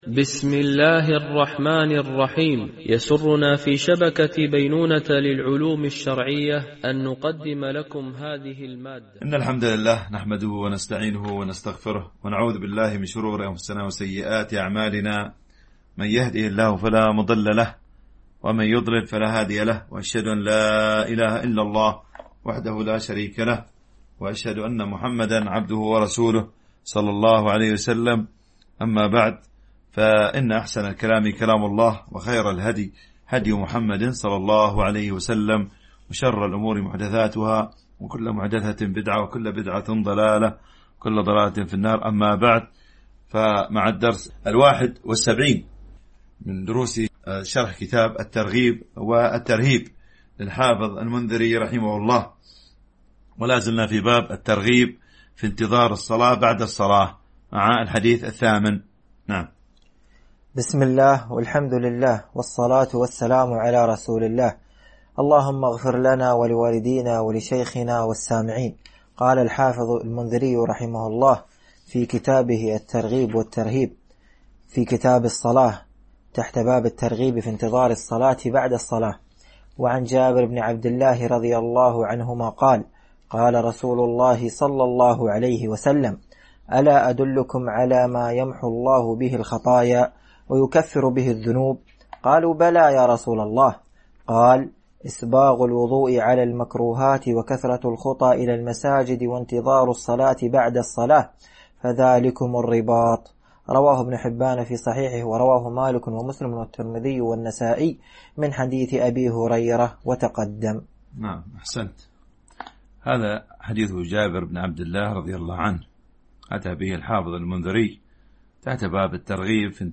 MP3 Mono 44kHz 32Kbps (CBR)